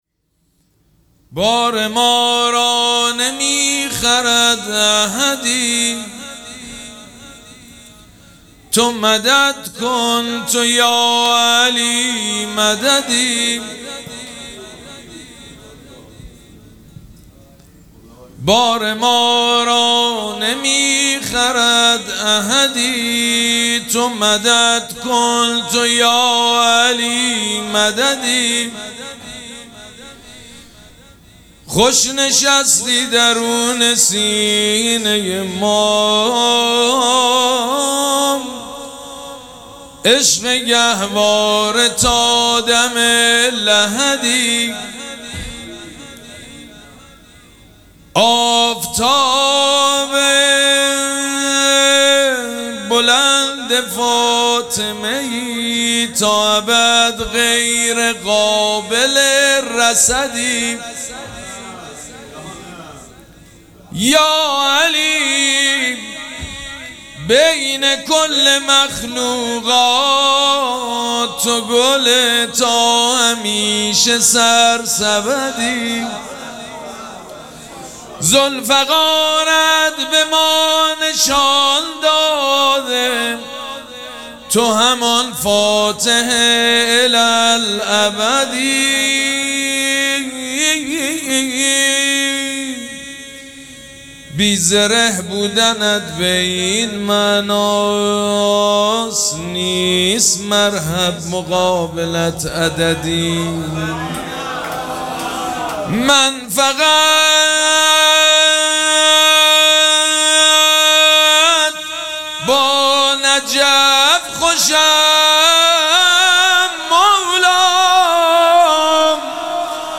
مراسم جشن ولادت حضرت زینب سلام‌الله‌علیها
شعر خوانی